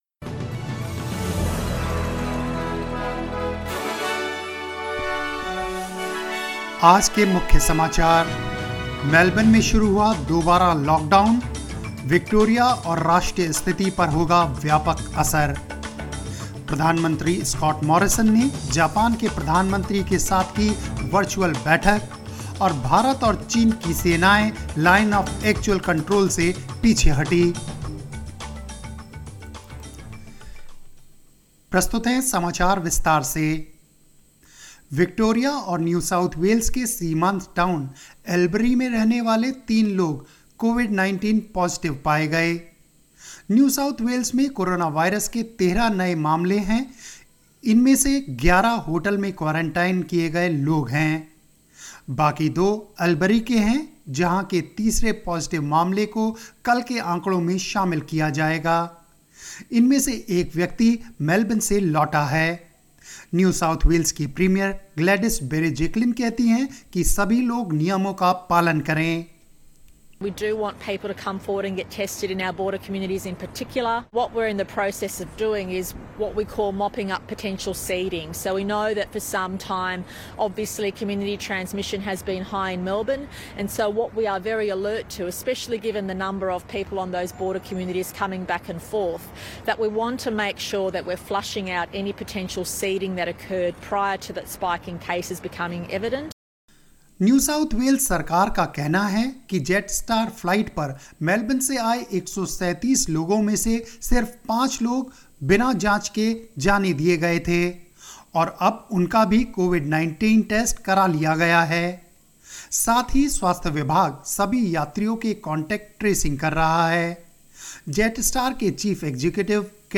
News in Hindi 09 July 2020